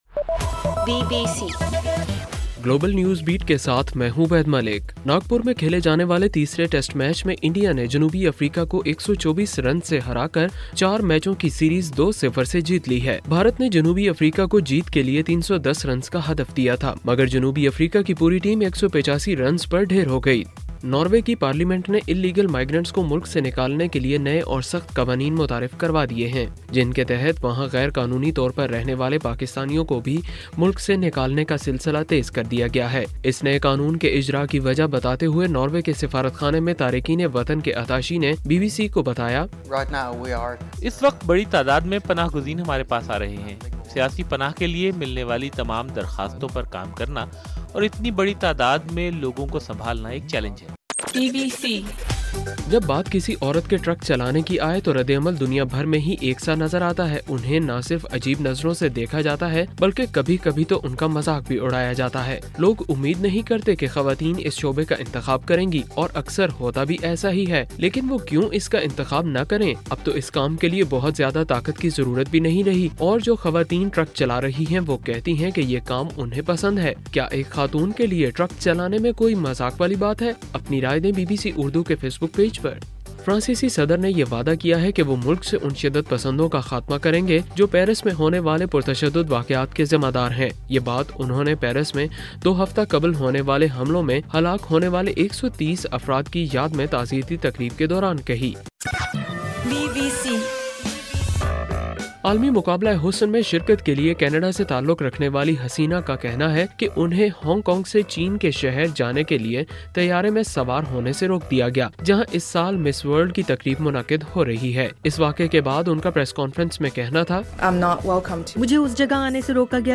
نومبر 27: رات 10 بجے کا گلوبل نیوز بیٹ بُلیٹن